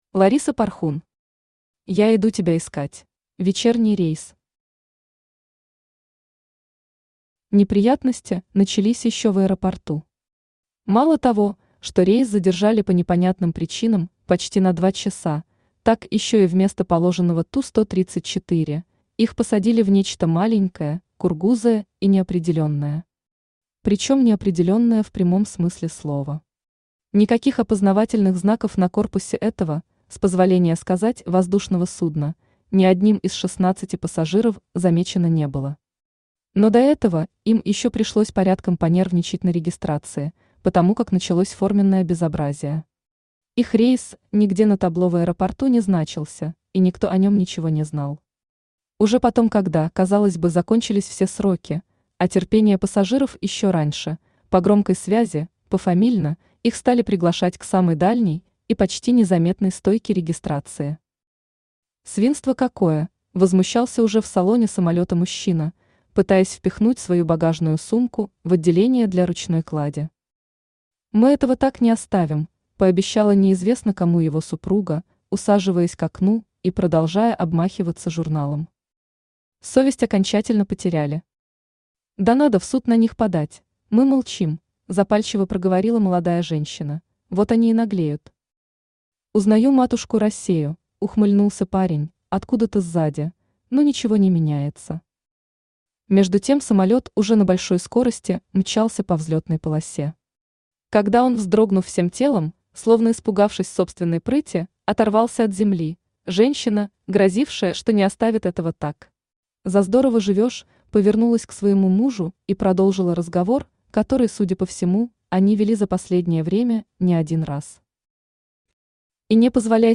Аудиокнига Я иду тебя искать…
Автор Лариса Порхун Читает аудиокнигу Авточтец ЛитРес.